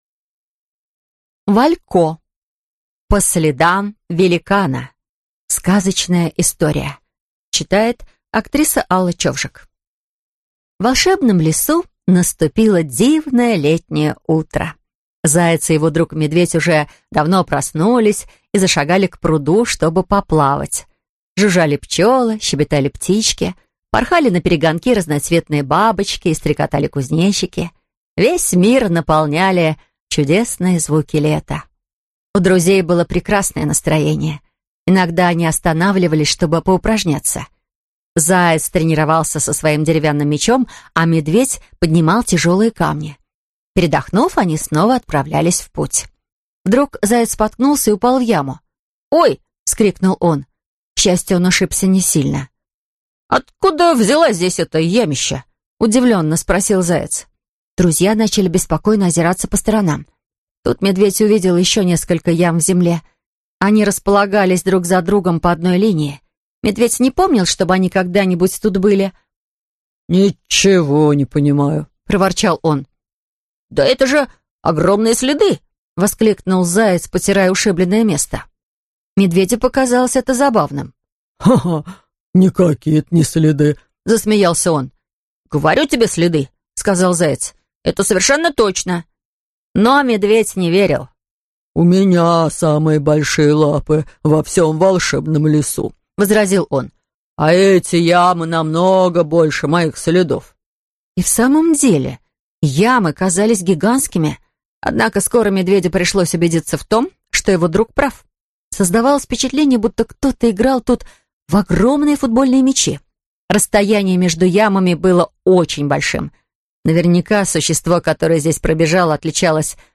Аудиокнига По следам великана | Библиотека аудиокниг